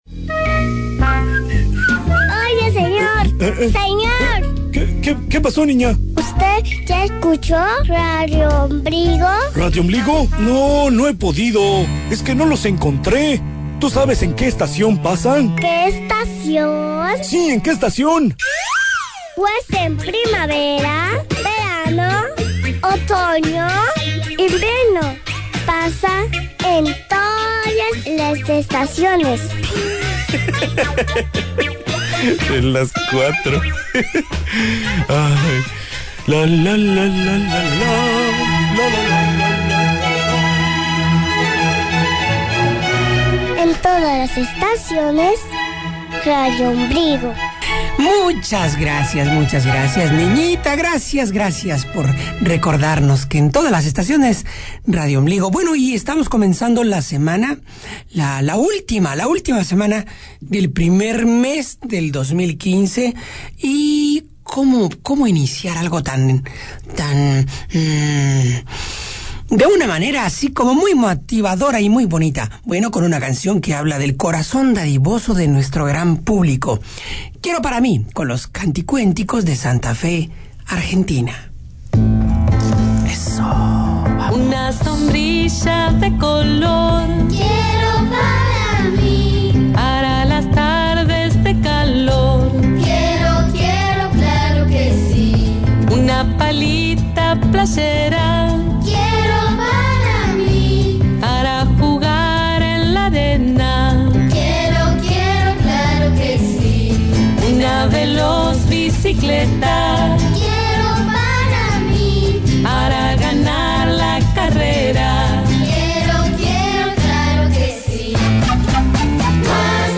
Disfruta la música de Wagner y de Maurice Ravel.